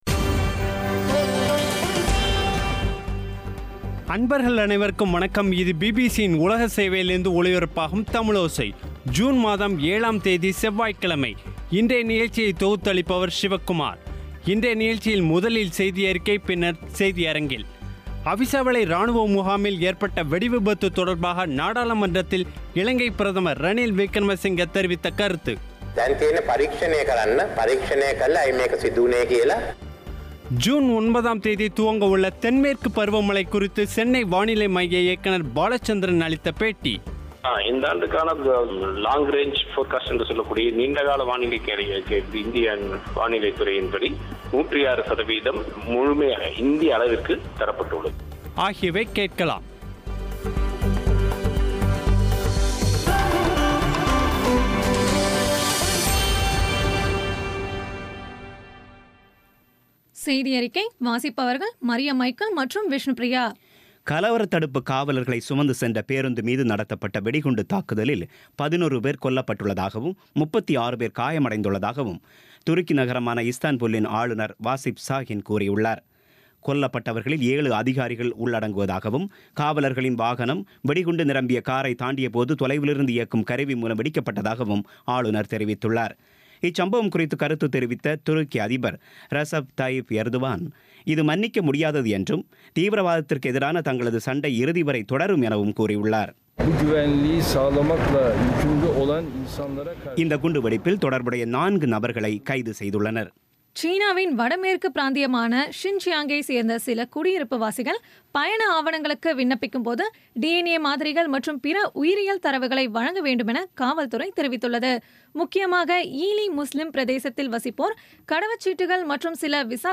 இன்றைய நிகழ்ச்சியில் முதலில் செய்தியறிக்கை பின்னர் செய்தியரங்கில்